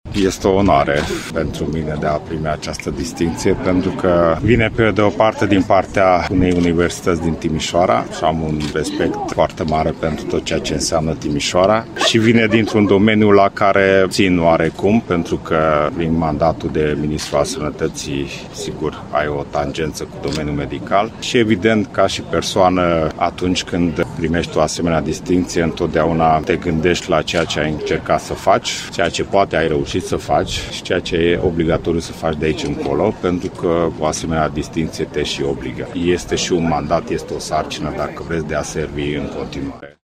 Evenimentul a avut loc în Aula Magna a UMF, unde ministrul a fost prezent, alături de soție, de mamă și de cei doi copii.